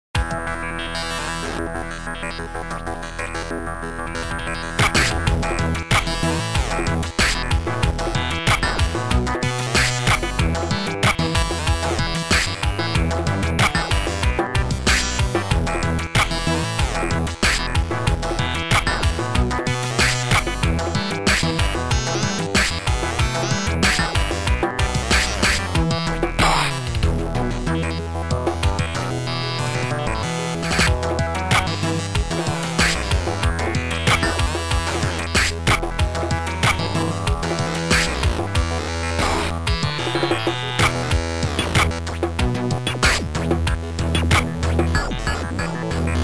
Sound Format: Noisetracker/Protracker